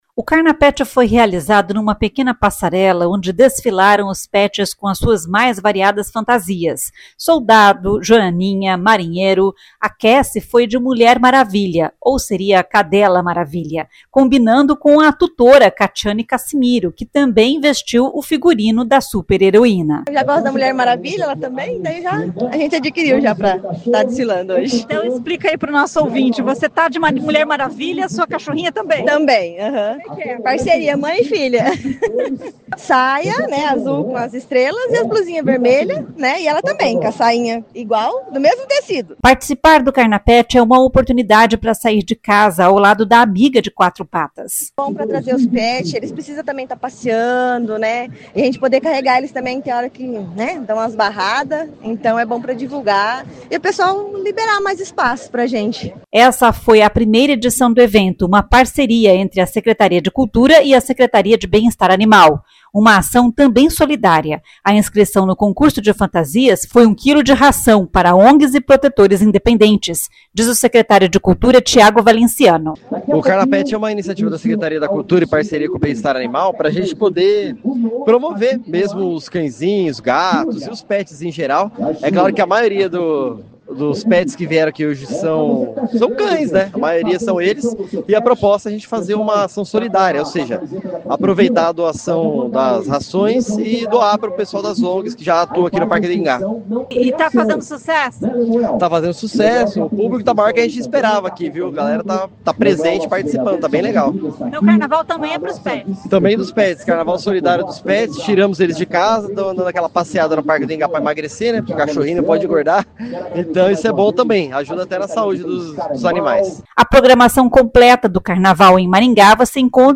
A inscrição no concurso de fantasias foi um quilo de ração para ONGs e protetores independentes, diz o secretário de Cultura Tiago Valenciano.